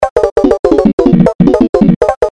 bowl struck
描述：Tibetan singing bowl struck once with a wooden mallet
标签： metal metallic bell ethnic percussion tibetansingingbowl
声道立体声